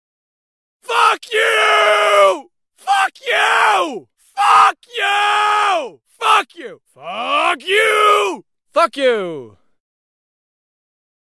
F**K You (Male)
Fk-YOU-Male.mp3